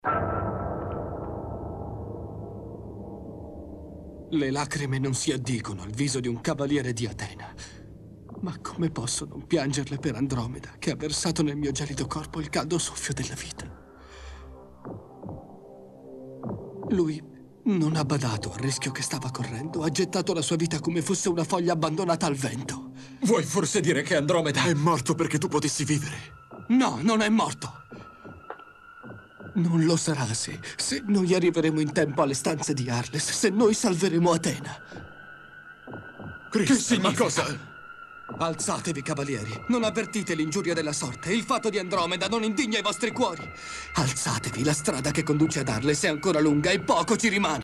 dal cartone animato "I cavalieri dello Zodiaco", in cui doppia Crystal il Cigno.